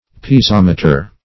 Piezometer \Pi`e*zom"e*ter\, n. [Gr.